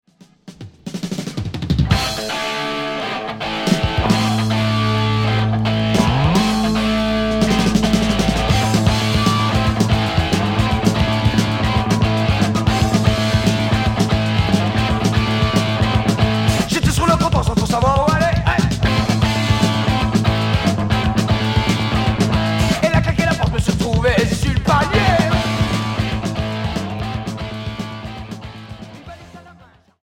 Rock hard punk